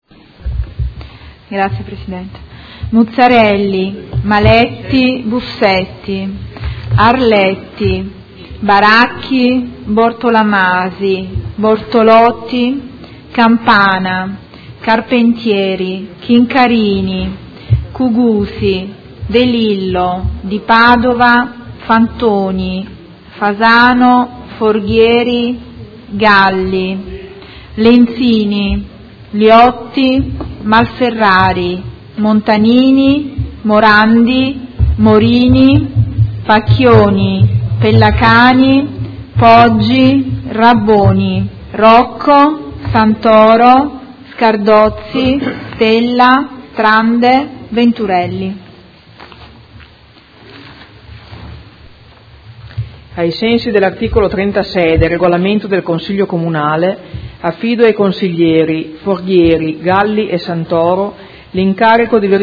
Seduta del 22/09/2016 Appello
Segretario Generale